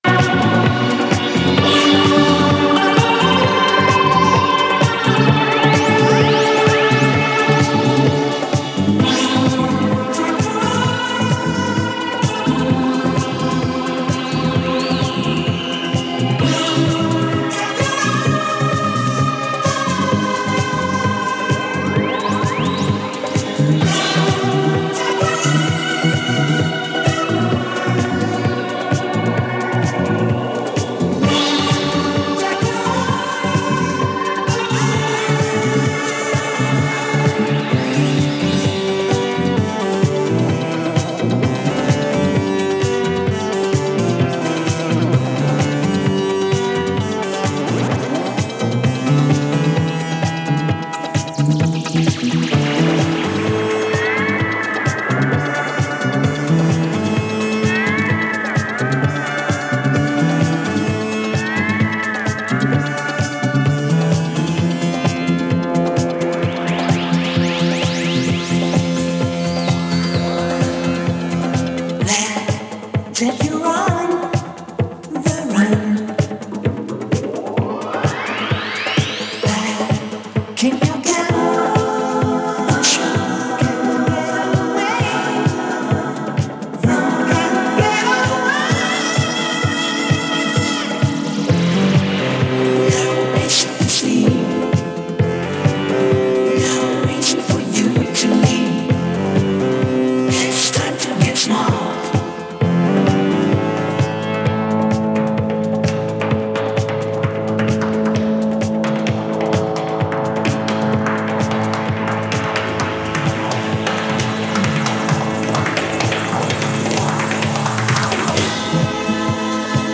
フランス南部はトゥールース出身のシンガーによるファースト・アルバム。